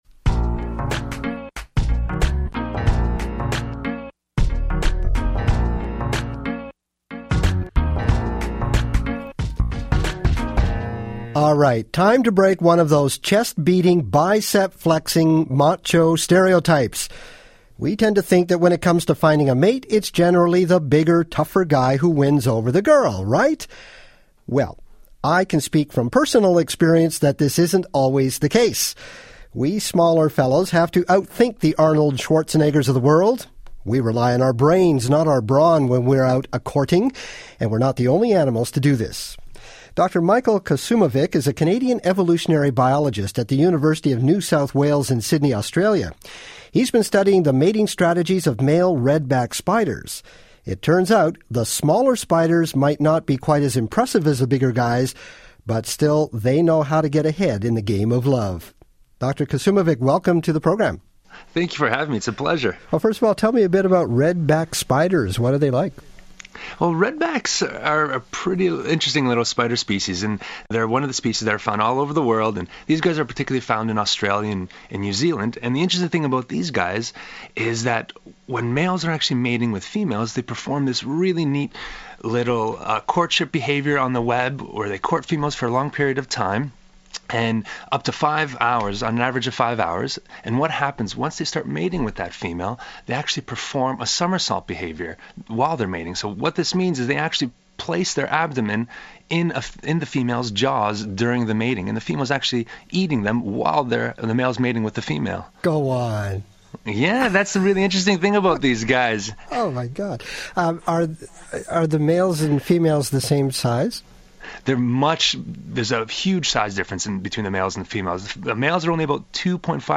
Radio Interview – Spider size doesn’t matter